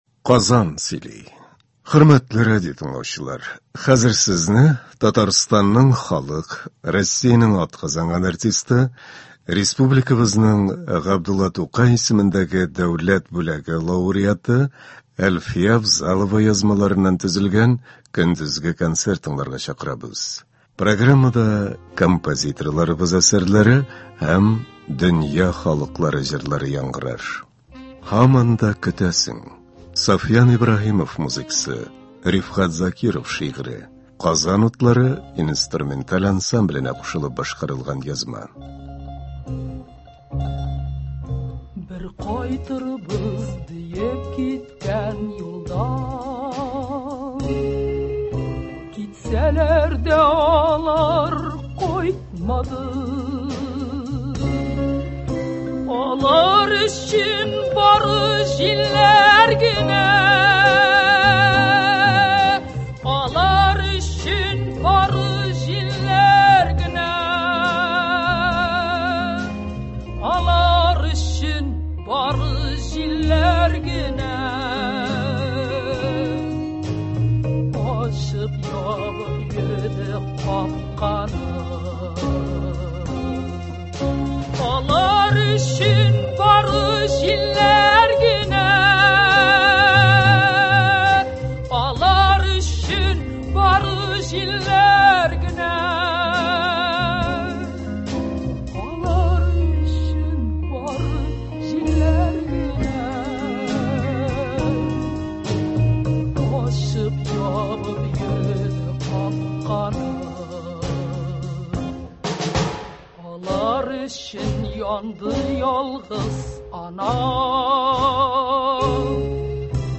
Көндезге концерт.
Башкортостан артистлары концерты.